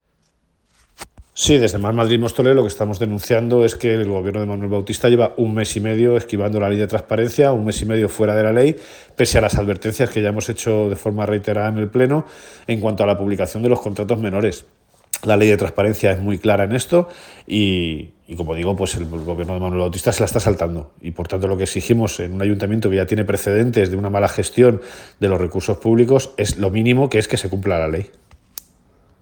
declaraciones-emilio-delgado-contratos-menores.mp3